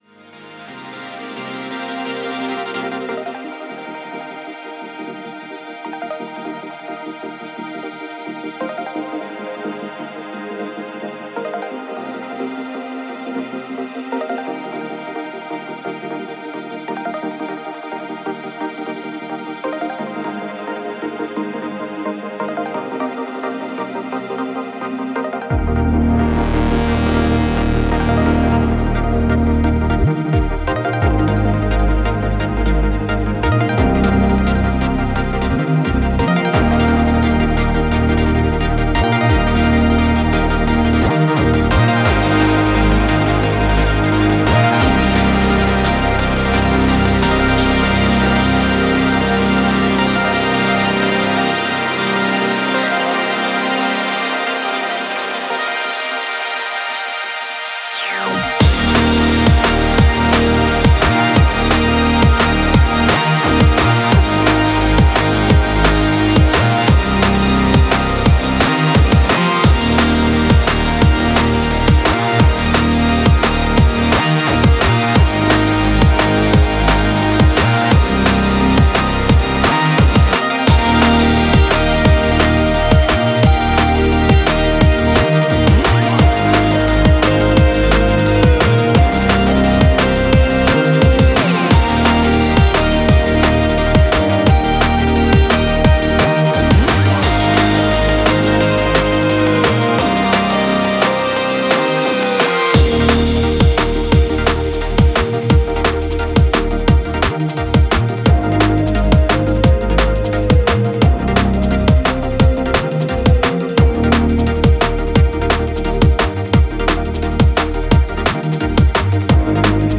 drum and bass
electronic music